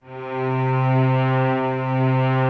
Index of /90_sSampleCDs/Optical Media International - Sonic Images Library/SI1_Swell String/SI1_Slow Swell
SI1 SWELL06L.wav